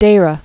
(dārə)